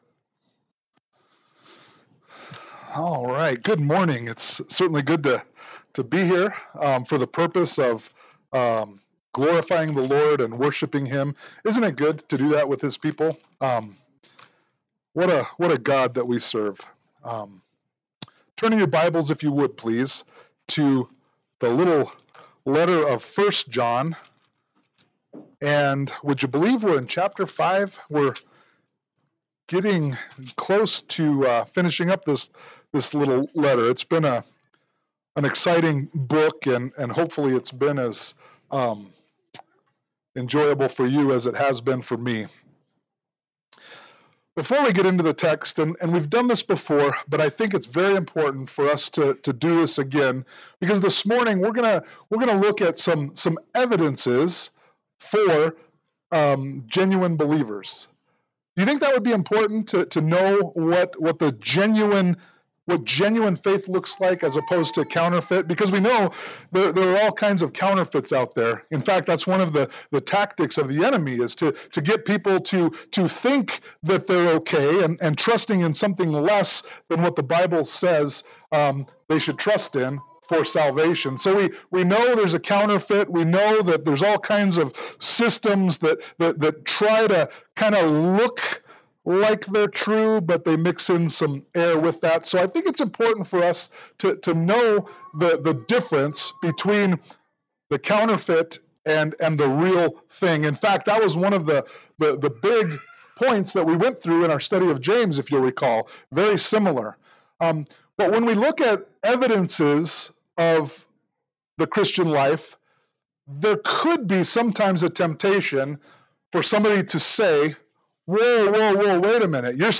1 John 5:1-5 Service Type: Sunday Morning Worship « 1 John 4:17-21